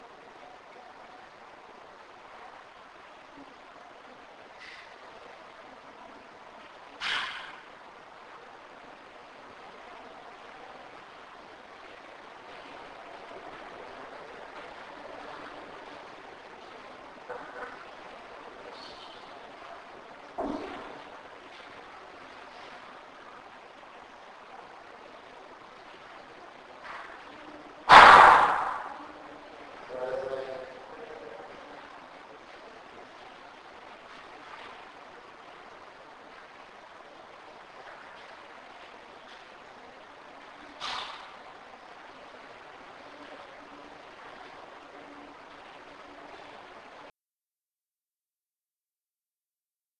There were no footsteps.